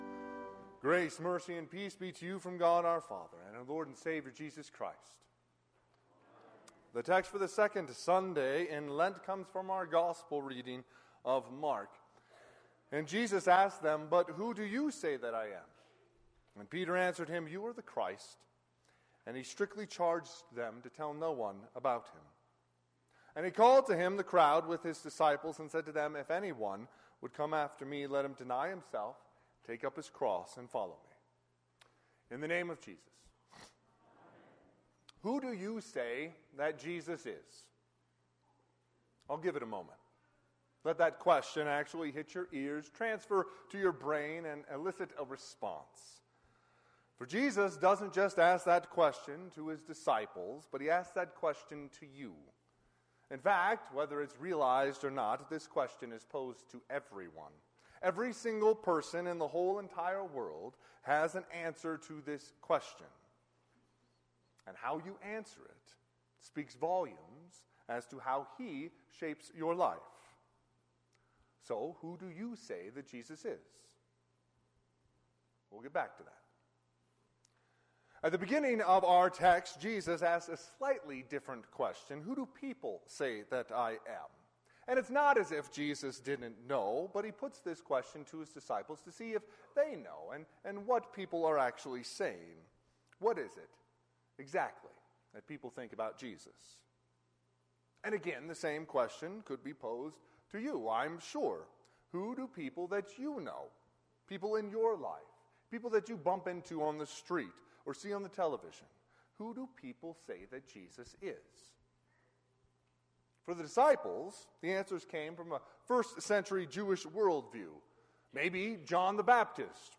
Sermon - 2/25/2024 - Wheat Ridge Evangelical Lutheran Church, Wheat Ridge, Colorado
Second Sunday in Lent